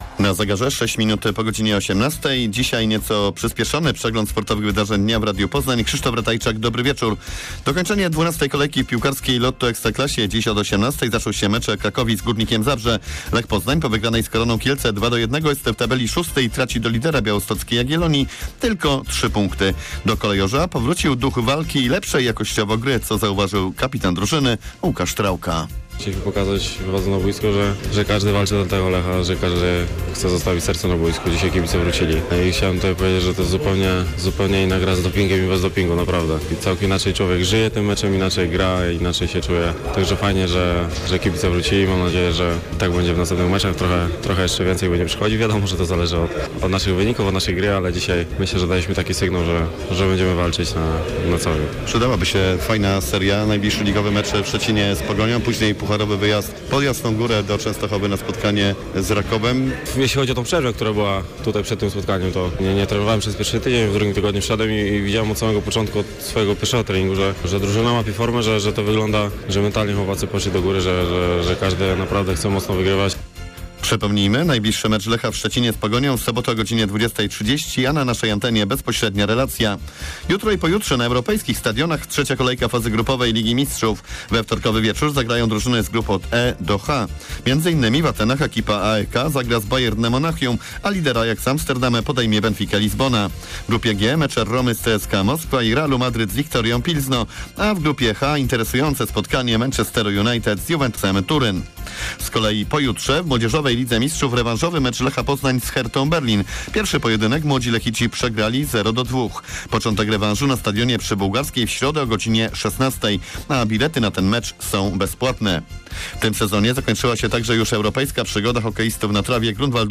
22.10. serwis sportowy godz. 18:05
W poniedziałkowym serwisie sportowym wrócimy do zwycięstwa Lecha Poznań nad Koroną Kielce w rozmowie z kapitanem Kolejorza Łukaszem Trałką. Ponadto o występie laskarzy Grunwaldu Poznań w hokejowej Lidze Mistrzów.